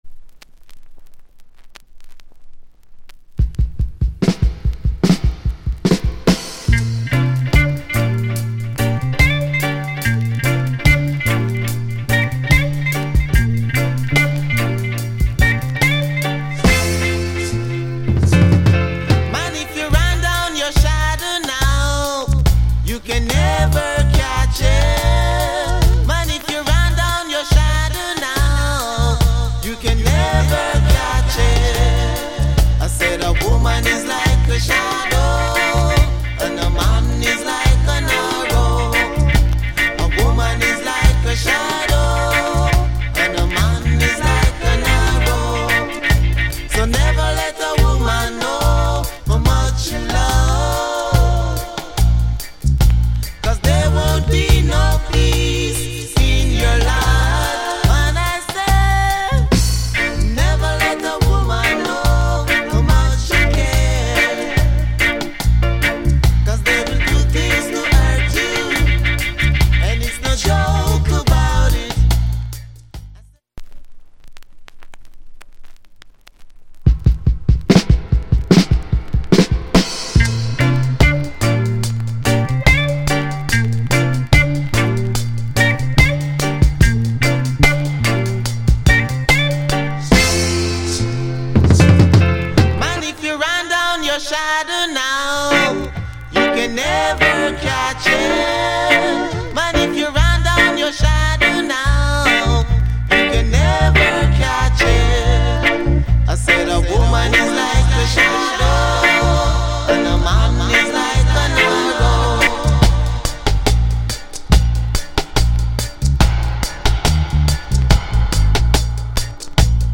Genre Reggae70sLate / Male Vocal Group Vocal